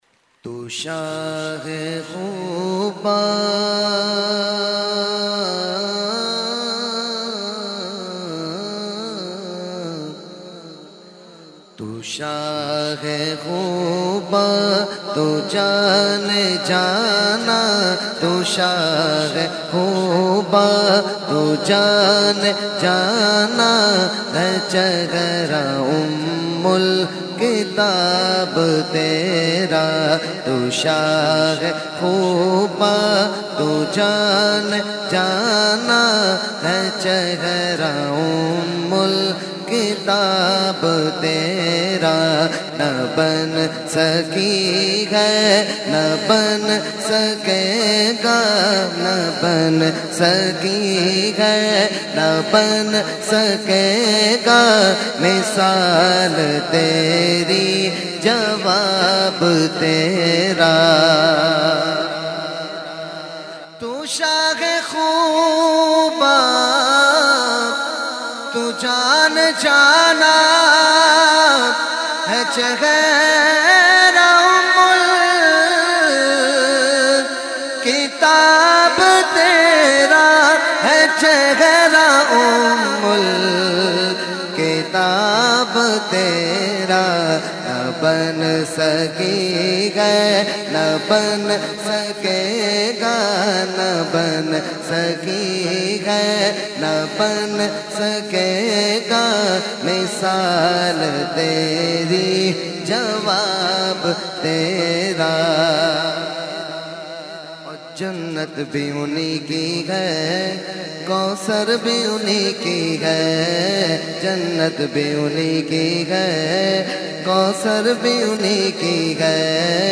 held on 21,22,23 December 2021 at Dargah Alia Ashrafia Ashrafabad Firdous Colony Gulbahar Karachi.
Category : Naat | Language : UrduEvent : Urs Qutbe Rabbani 2021-2